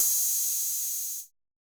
UHH_ElectroHatC_Hit-31.wav